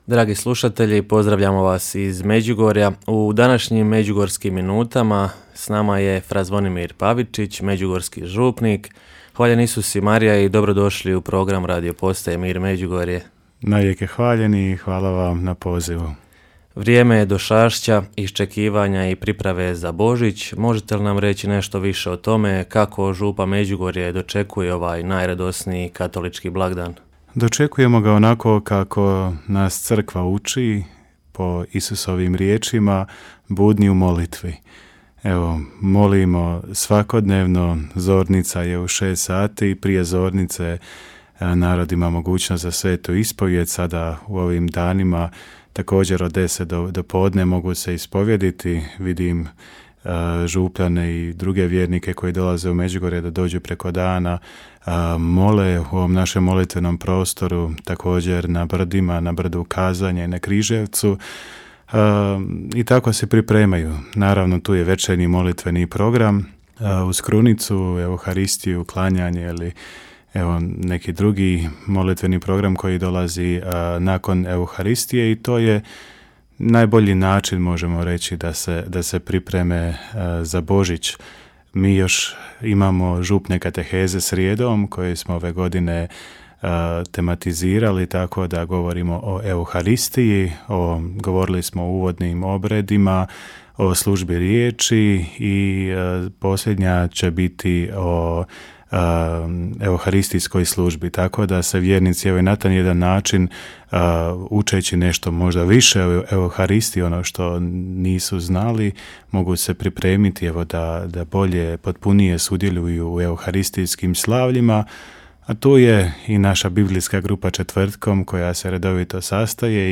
Tema razgovora je bila došašće, vrijeme iščekivanja i pripreme za Božić u župi Međugorje.